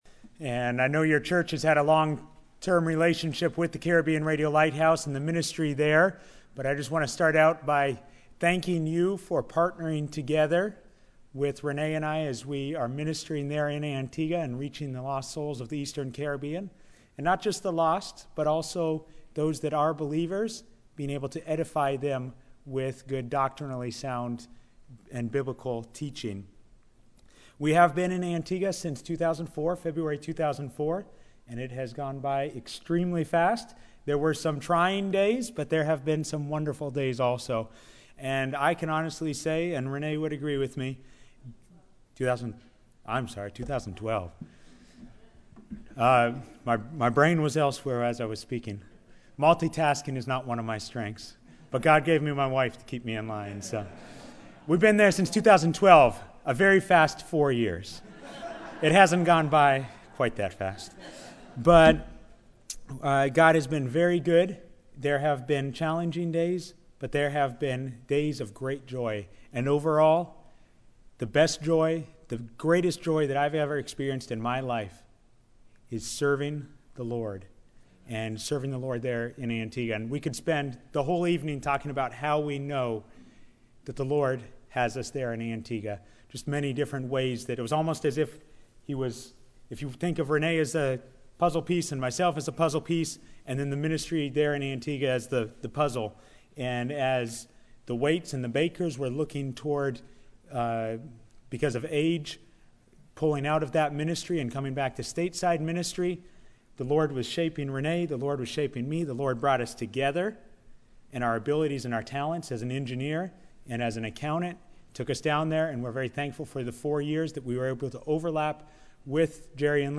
Bible Text: Acts 28 | Preacher: Missionary